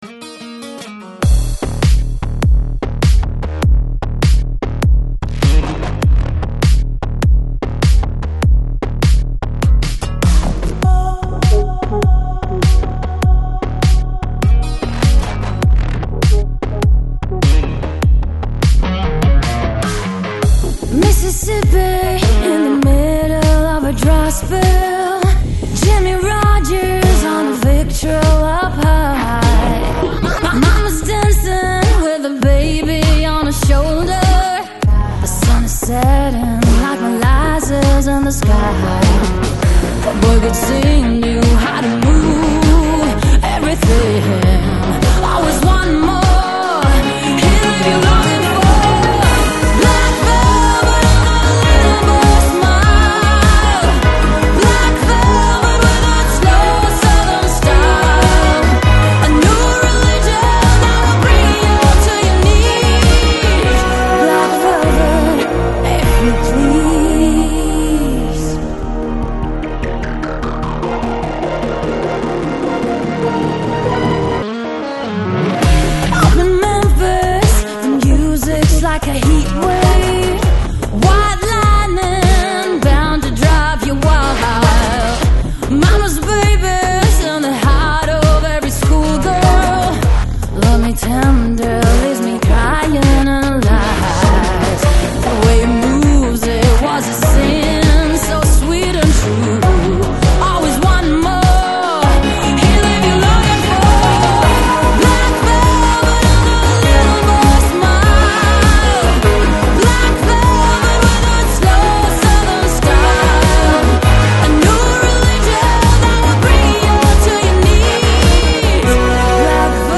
Psychedelic Trance, Electronic Издание